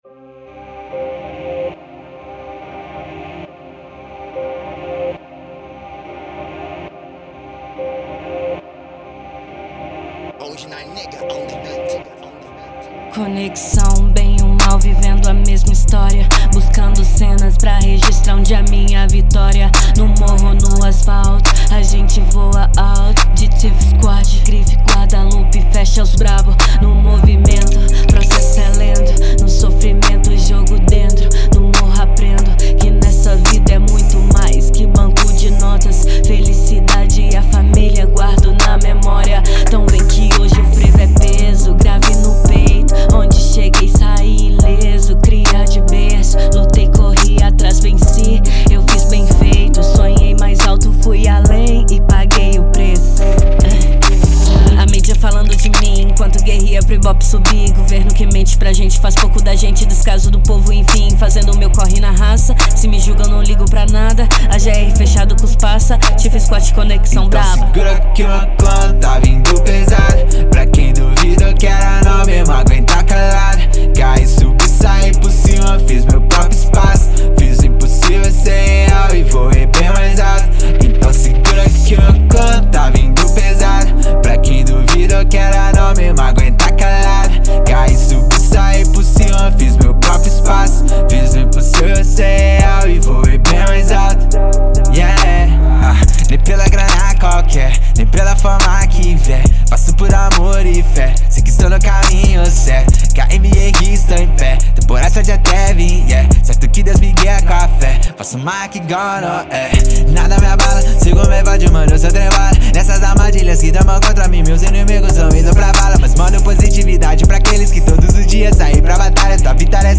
EstiloTrap